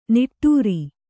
♪ niṭṭūri